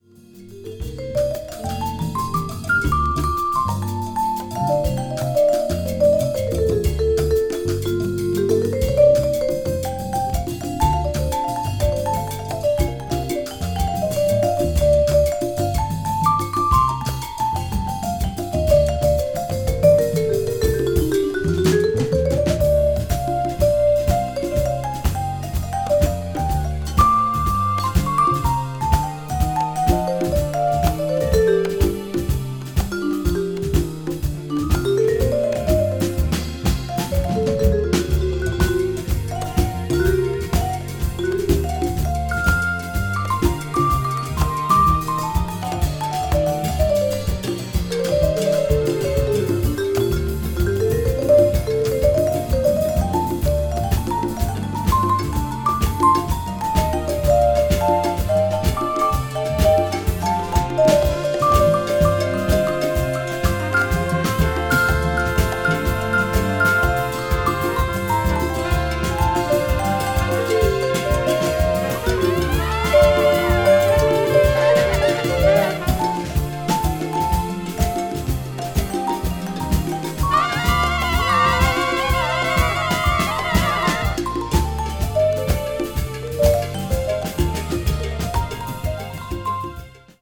afro jazz   contemporary jazz   ethnic jazz   jazz orchestra